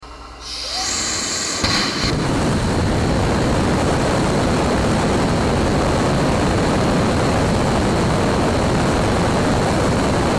Turbopomps sounds on spacex’s raptor